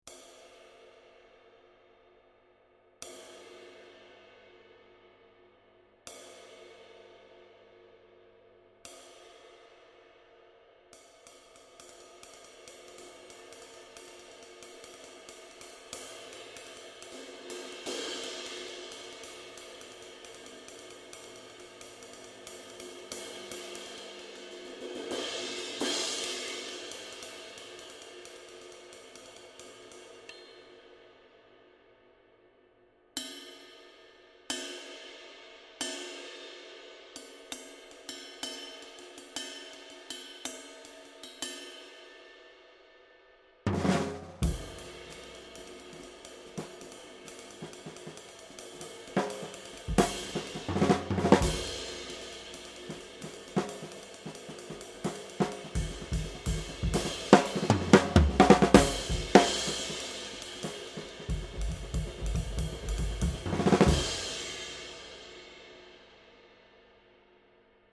22" New Orleans Thin Ride 2386g